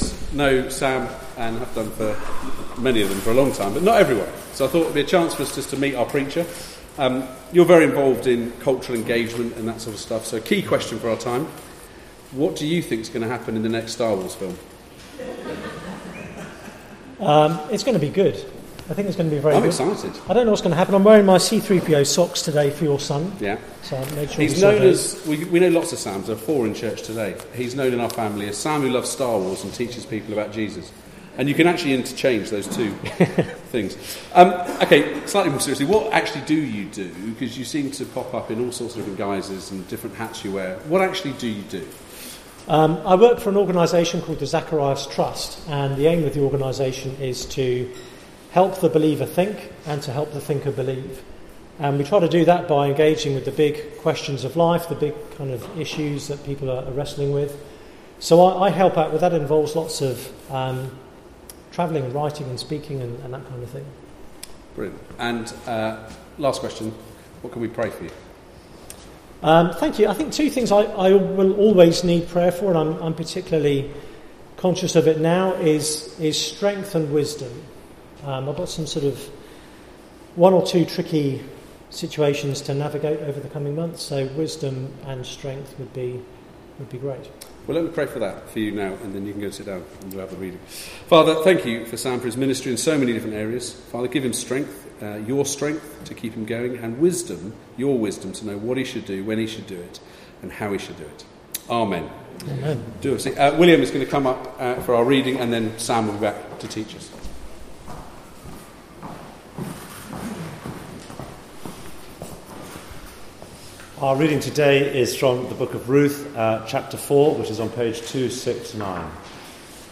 Passage: Ruth 4: 1-22 Service Type: Weekly Service at 4pm Bible Text